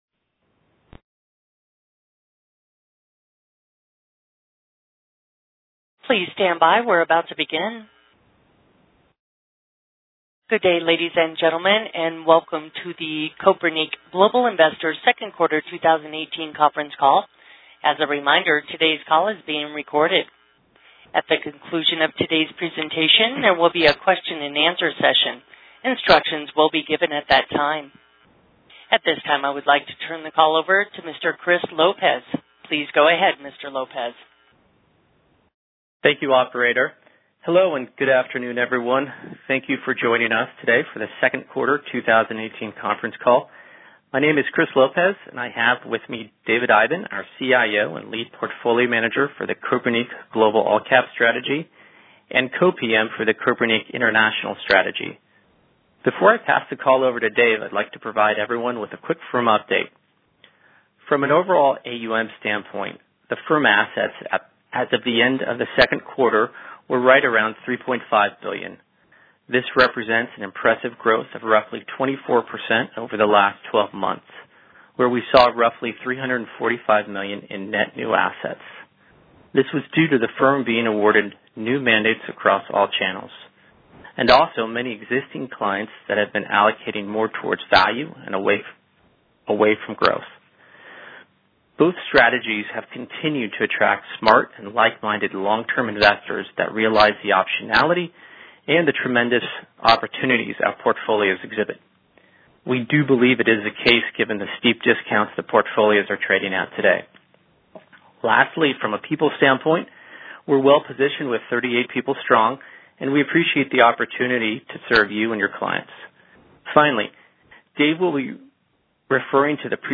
Q2 2018 Conference Call - Kopernik Global Investors